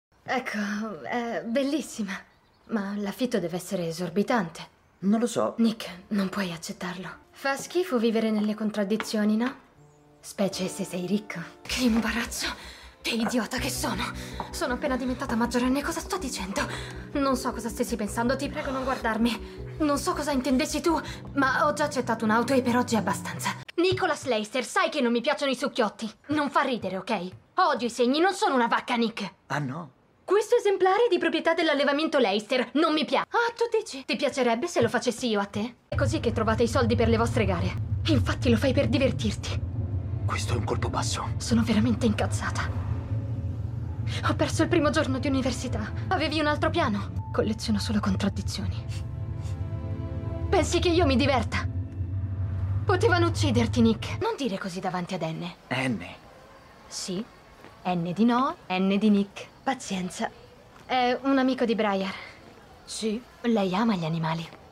nel film "È colpa tua?", in cui doppia Nicole Wallace.